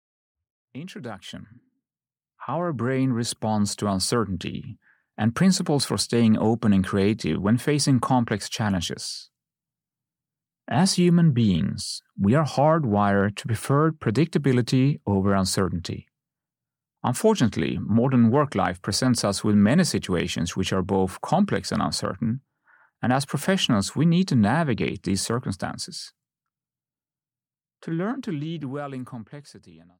Ukázka z knihy
navigating-in-complexity-introduction-en-audiokniha